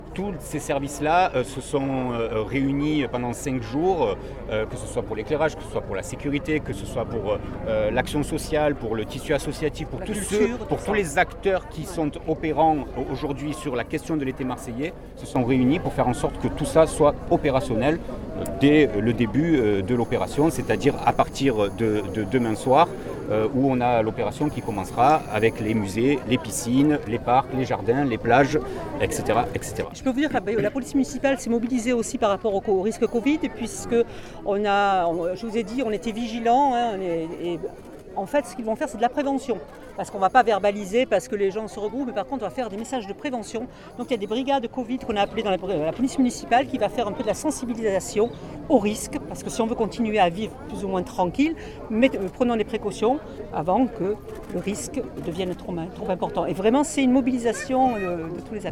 Et cet été qui prend place du 1er août au 23 septembre 2020 vient d’être lancé ce 28 juillet par la maire de Marseille Michèle Rubirola accompagnée par nombre de ses adjoints et un public venu en nombre pour saluer l’initiative…